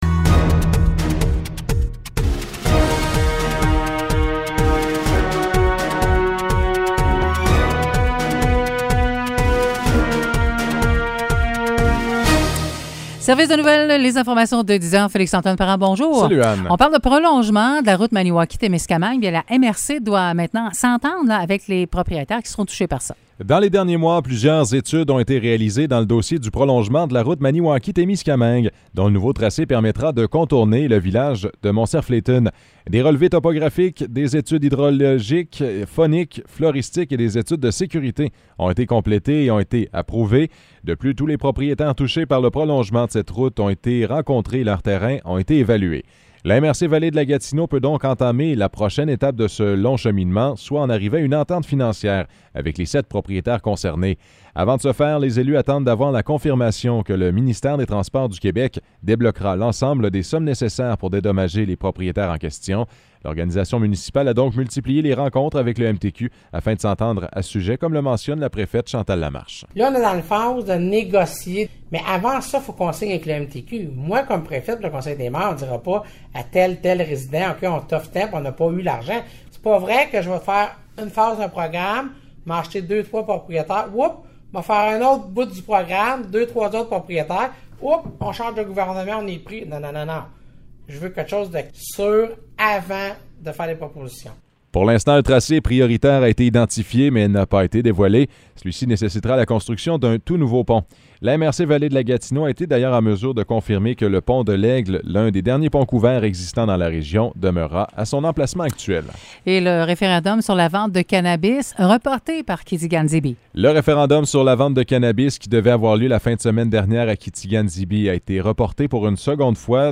Nouvelles locales - 25 août 2021 - 10 h